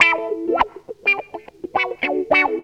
GTR 29 AM.wav